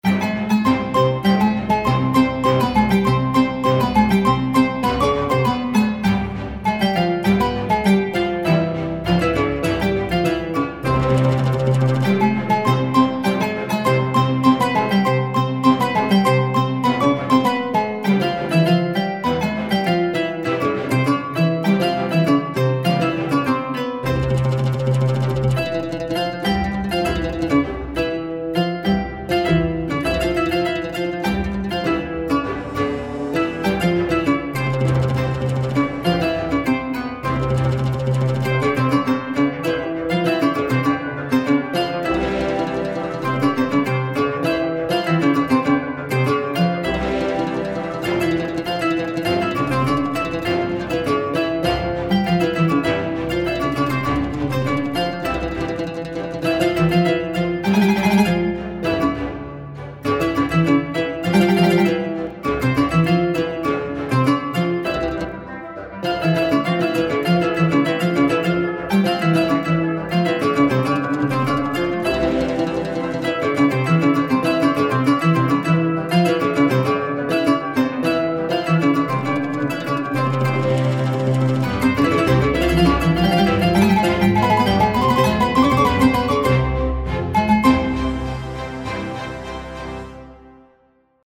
در دو مثال زیر از آکوردهایی با فواصل غربی و شرقی استفاده شده است.
• این دوملودی که با فواصل ریزپرده هارمونیزه شده بود ؛ فضای گنگ و مبهمی داشت و دلپذیر نبود فکر کنم به خاطر همین است که می گویند باید فواصل ریزپرده ای را برای هراونیزه تعدیل کرد ؛ البته اینکه اینها را از موسیقی سنتی حذف کنیم کاملا اشتباه است زیرا آنجا بافت تکصدایی است و لزومی ندارد ولی برای چندصدایی آهنگساز ناچار است.